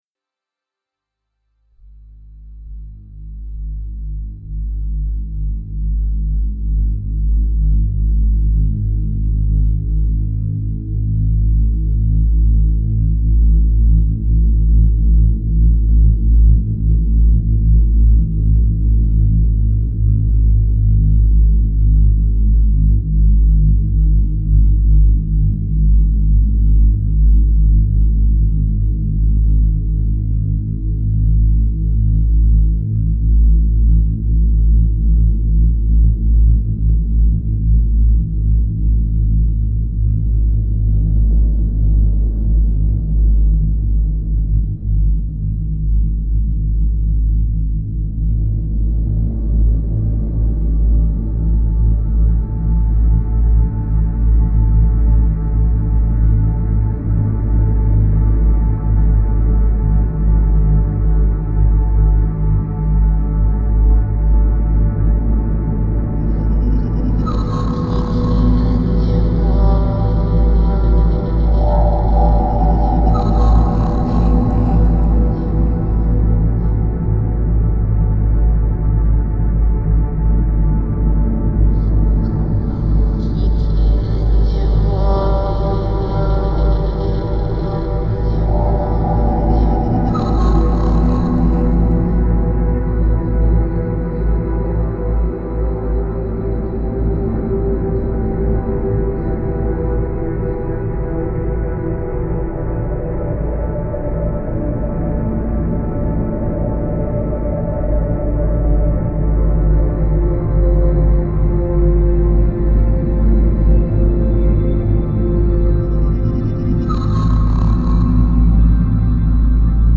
Genre: Dark Ambient.